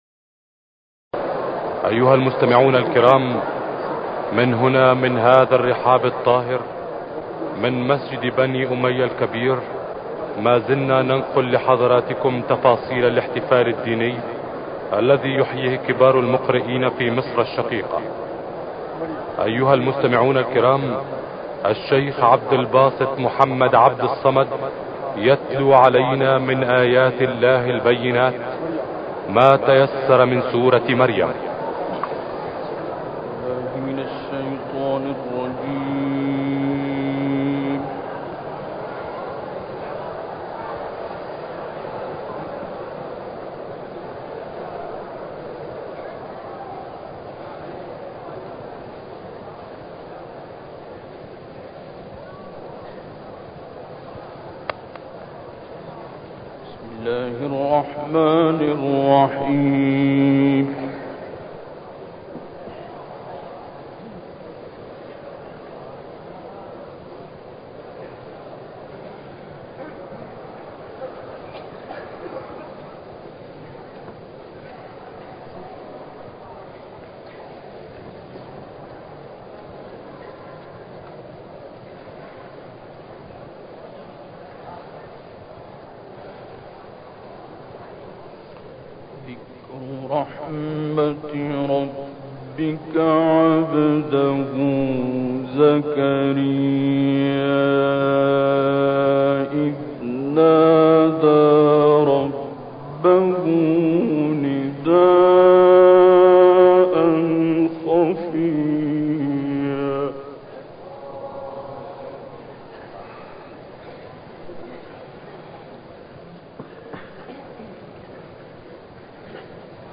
تلاوت «عبدالباسط» در مسجد اموی سوریه